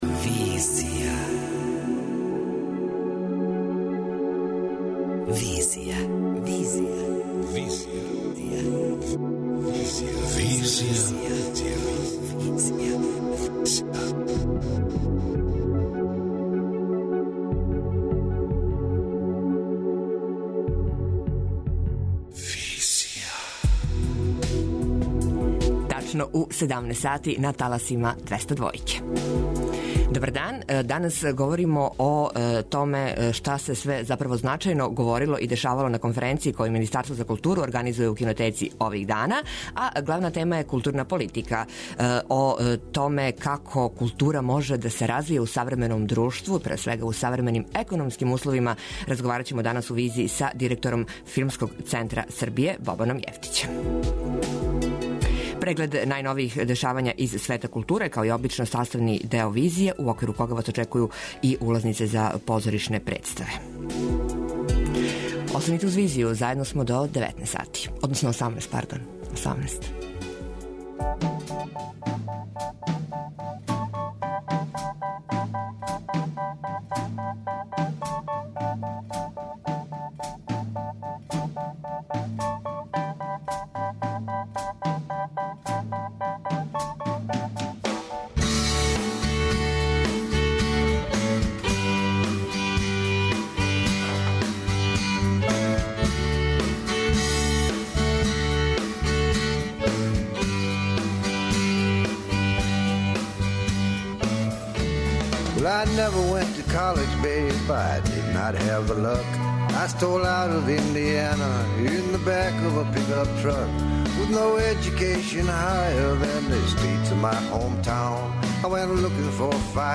преузми : 27.28 MB Визија Autor: Београд 202 Социо-културолошки магазин, који прати савремене друштвене феномене.